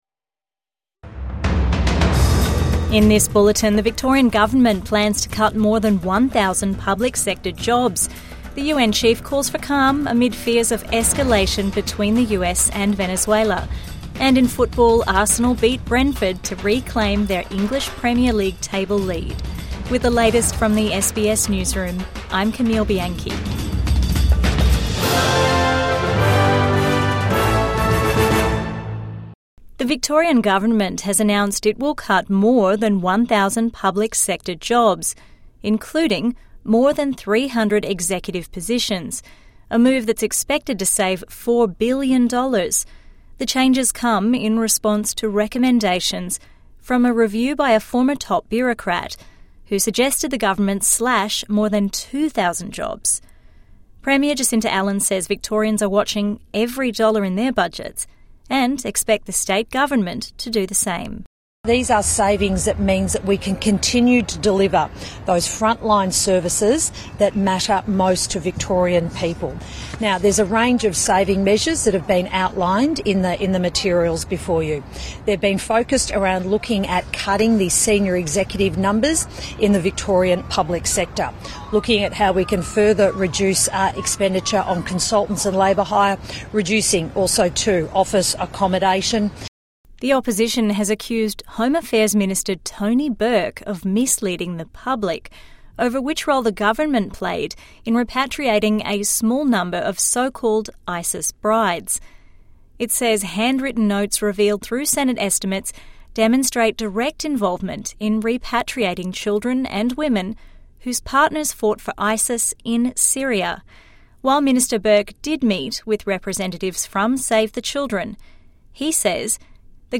Victoria to cut 1000 public sector jobs | Evening News Bulletin 4 December 2025